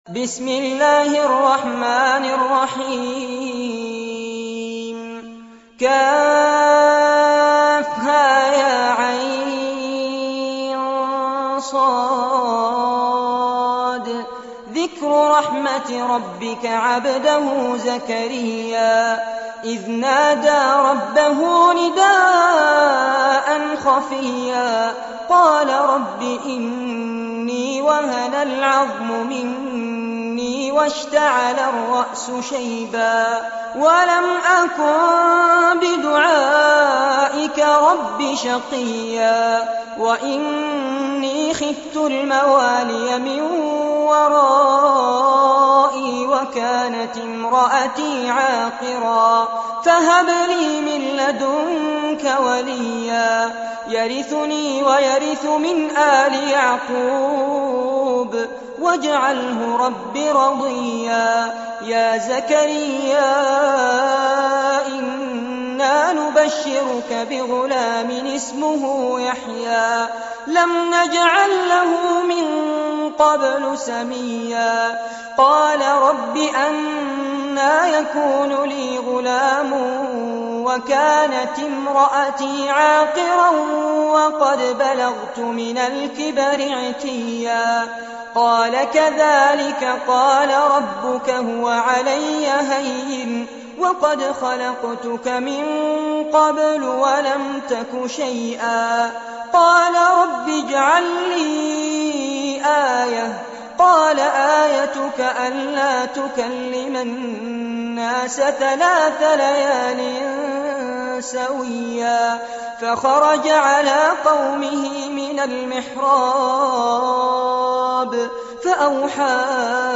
سورة مريم- المصحف المرتل كاملاً لفضيلة الشيخ فارس عباد جودة عالية - قسم أغســـــل قلــــبك 2